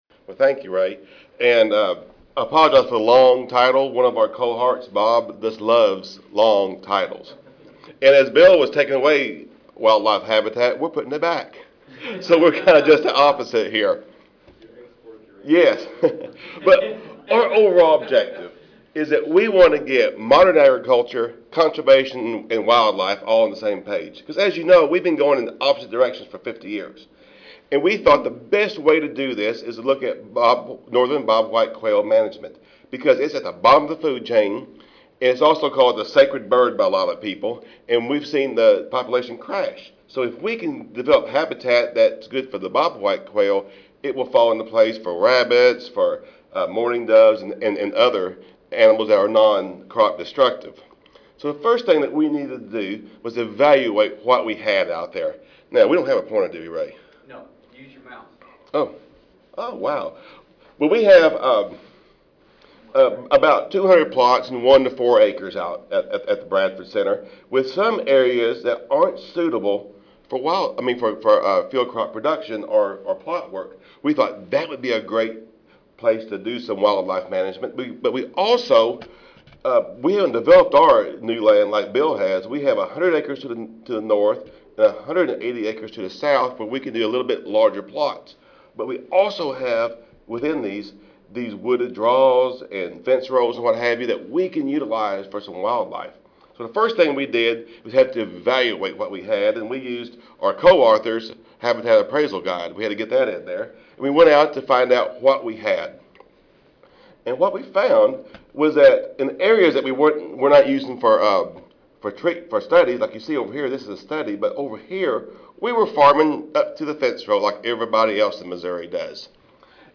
University of Missouri Audio File Recorded presentation